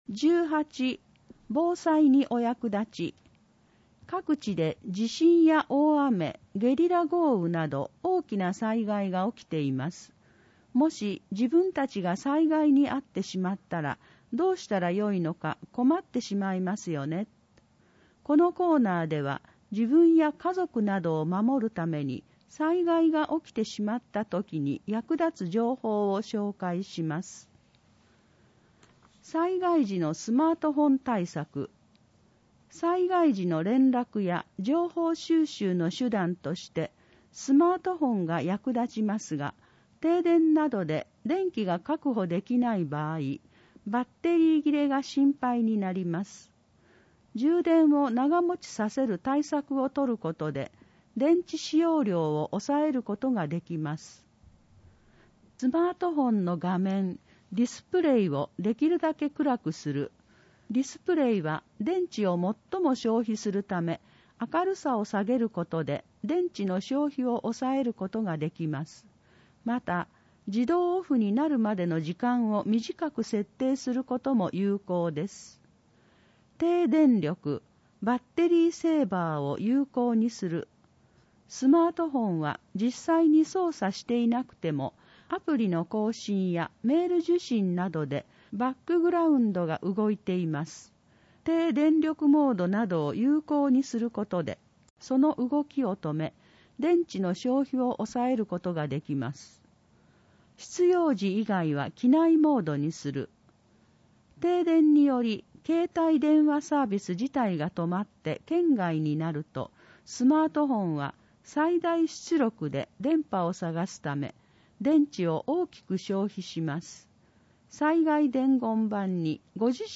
広報とうごう音訳版（2021年2月号）